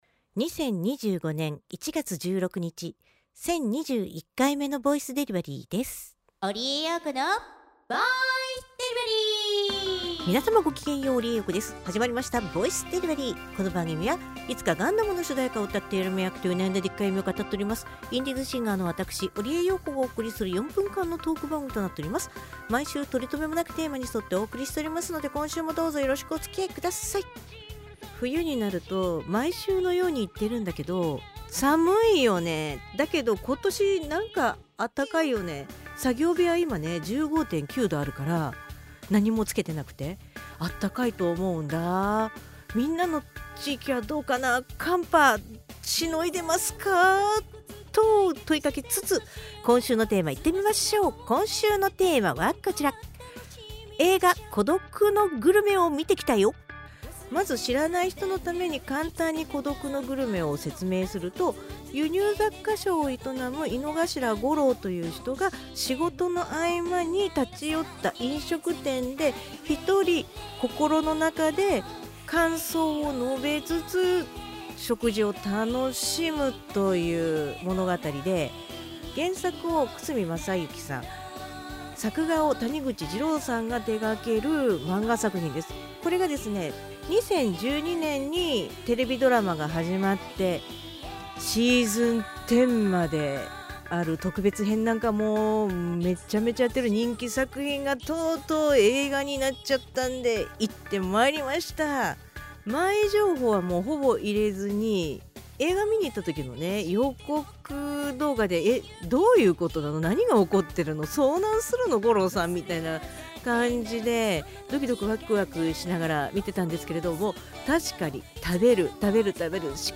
毎週水曜日か木曜日更新の４分間のトーク番組（通称：ぼいでり）時々日記とTwitterアーカイブ